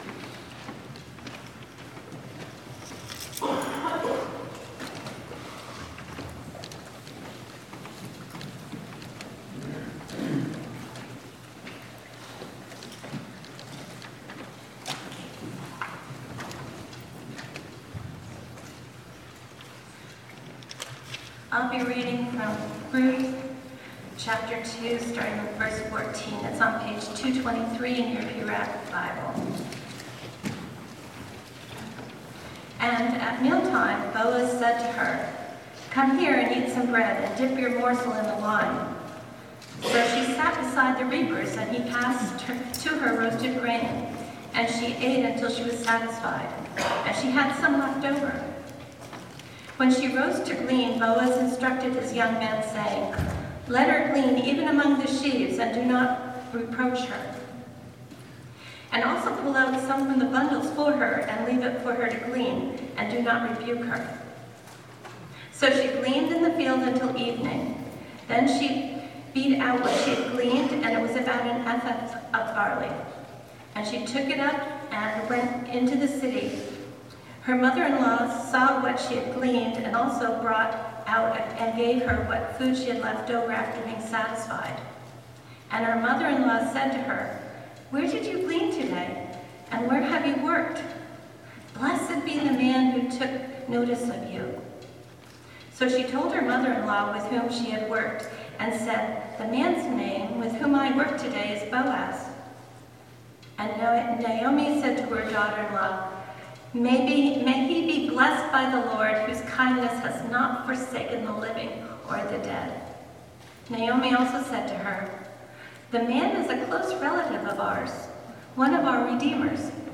Sermon
at GCC in Marblehead